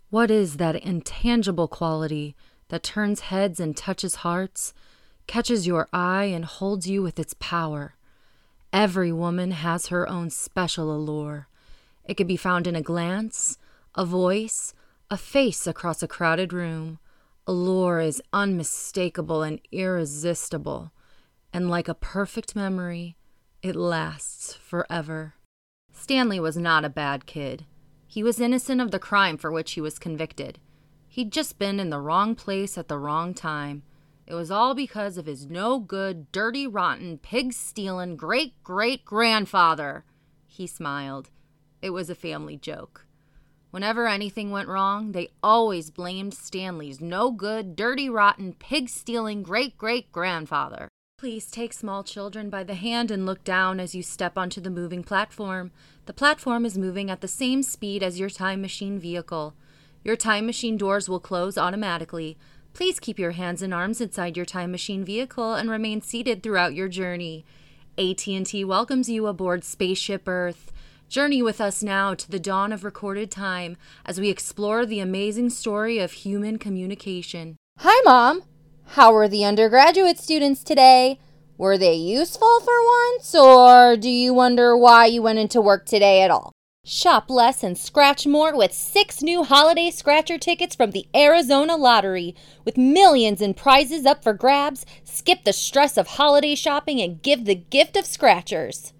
VO Reel